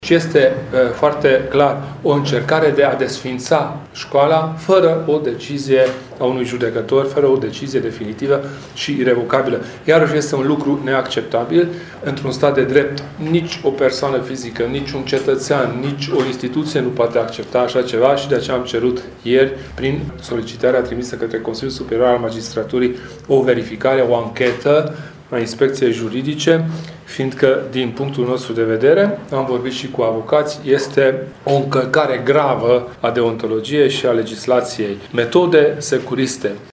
Prezent azi la Tg.Mureș președintele Uniunii, Kelemen Hunor s-a arătat indignat de felul în care se desfășoară ancheta DNA: